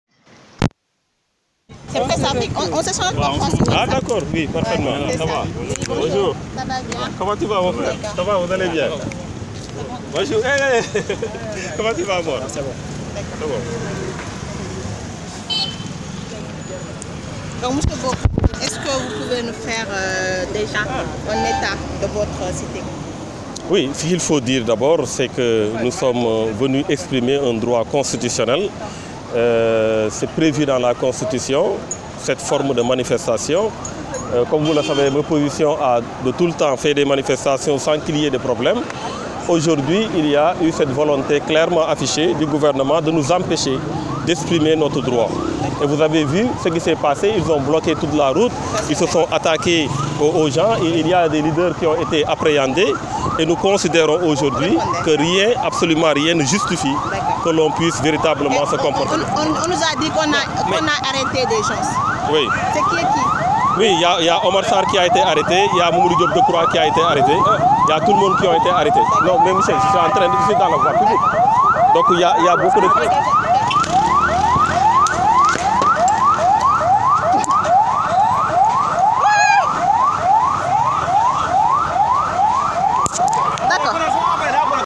Audio : L'arrestation de Thierno Bocoum en pleine interview avec les reporters de PressAfrik Thierno Bocoum pris par les forces de l'ordre alors qu'il était en pleine interview avec les reporters de PressAfrik. Revivez la scène à travers cet élément audio pris ce vendredi matin dans les rues de Sandaga !!!